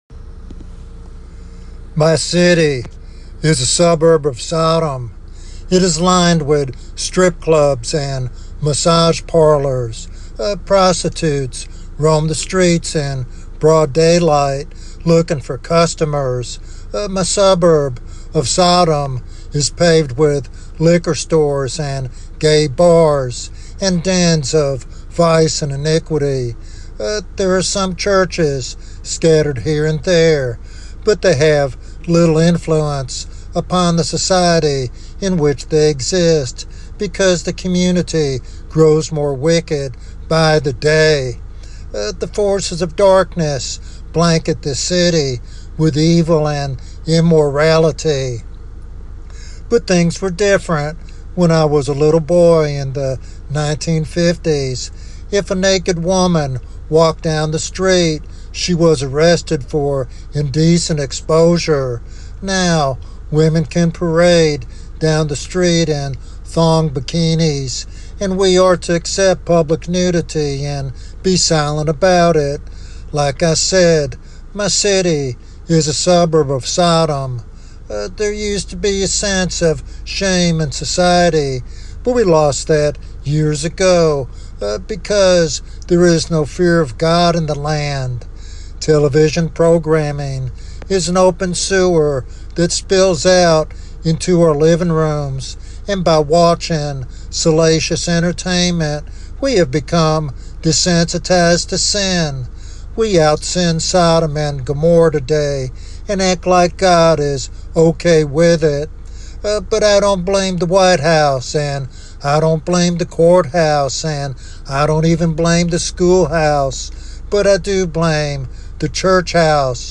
This sermon serves as a sobering wake-up call to both the church and the community.